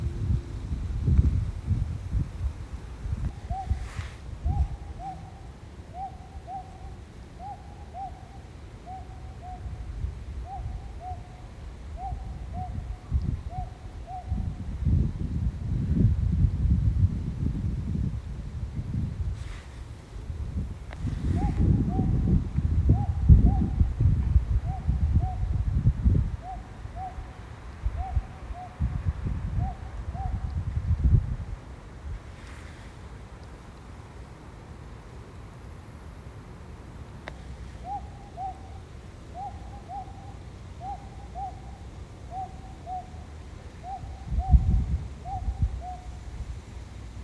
アオバズク